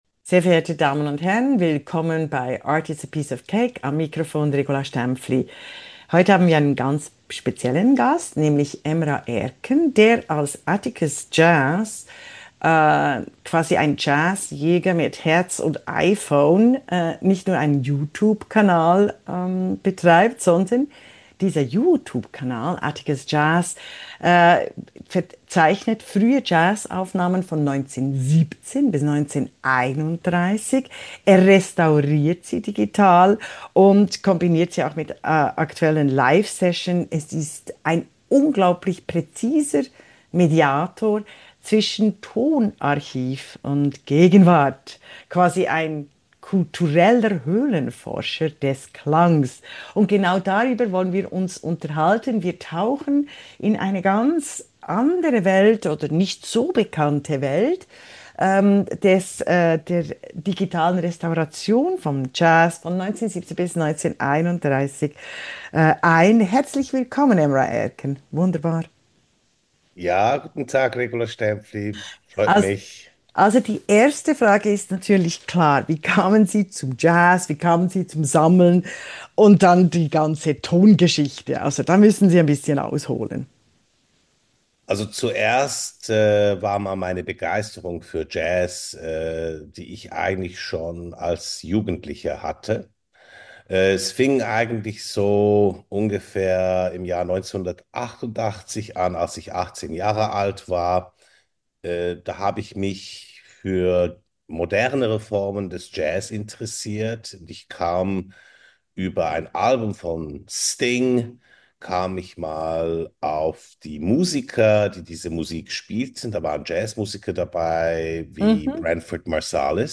ein wunderbares Kunst-Gespräch